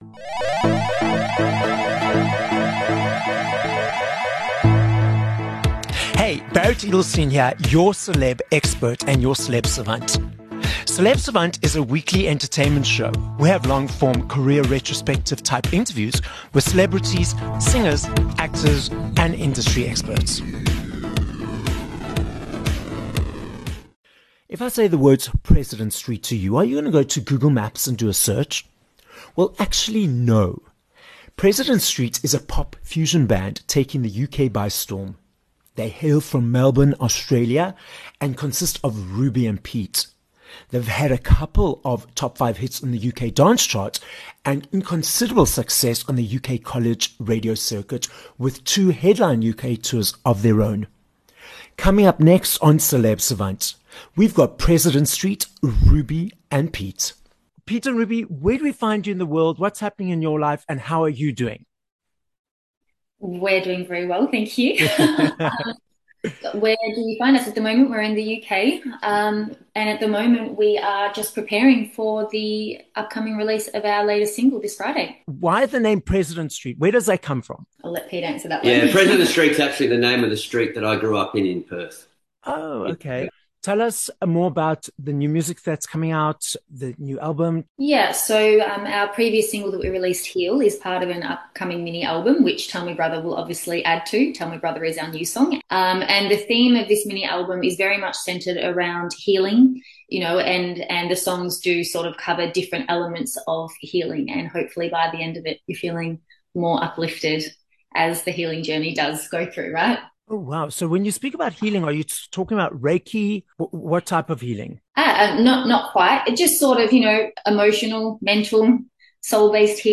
28 Nov Interview with President Street